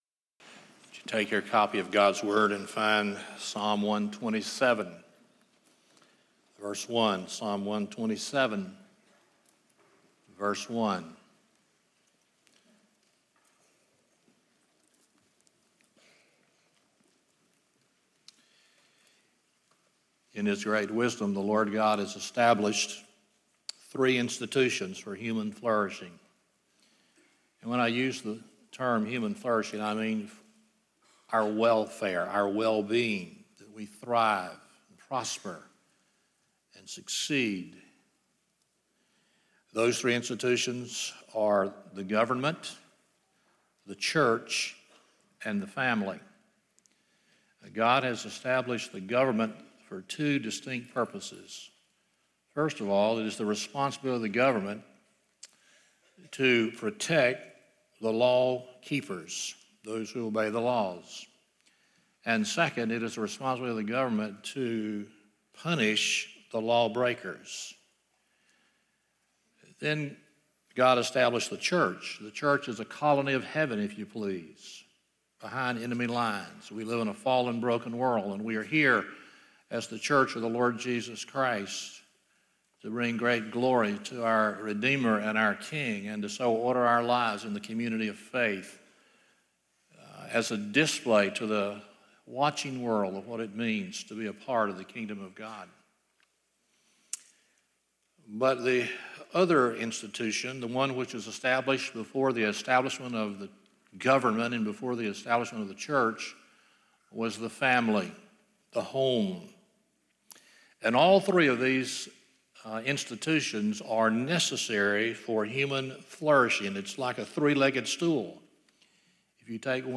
Psalm 127:1 Service Type: Sunday Morning 1.